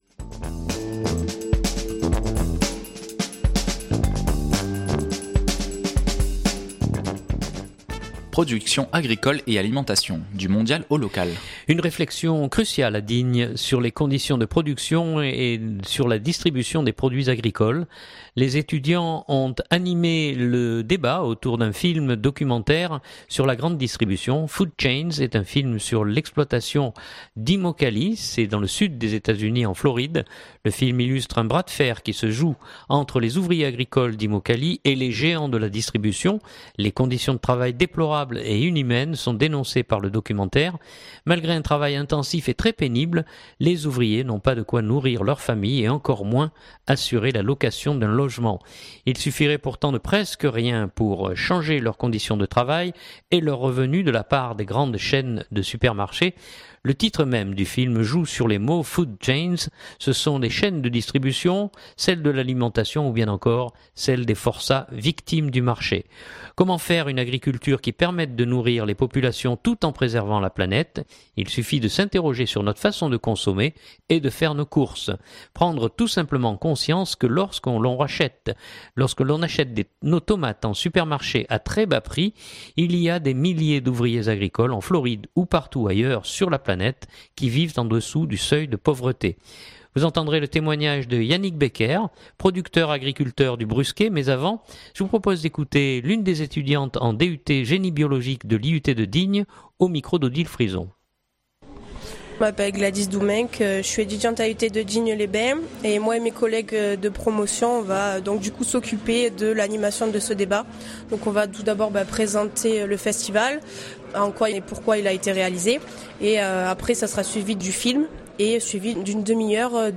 Une réflexion cruciale à Digne sur les conditions de production et sur la distribution des produits agricoles. Les étudiants ont animé le débat autour d’un film documentaire sur la grande distribution.